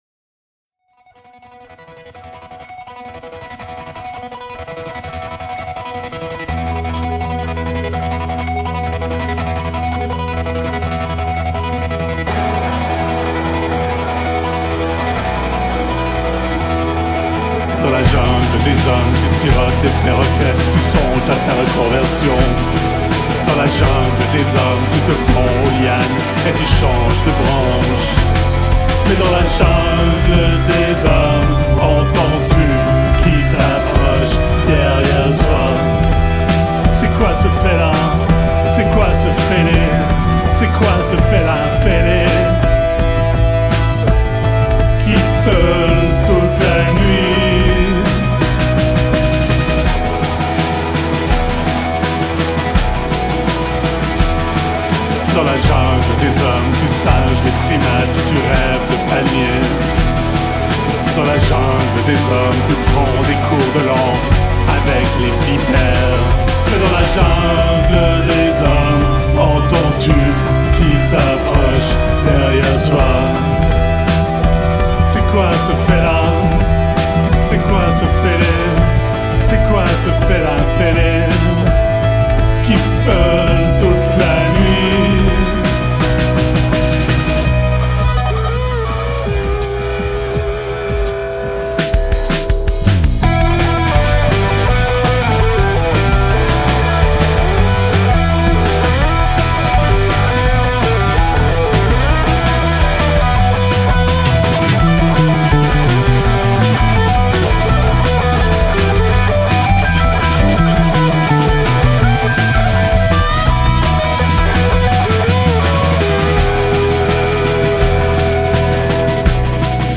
guitare, basse, clavier
Studio des Anges, Lausanne